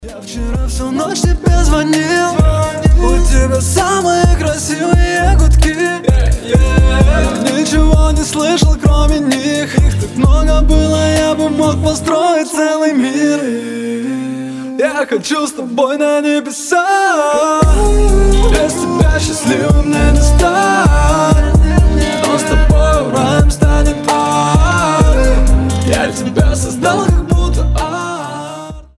мужской вокал
лирика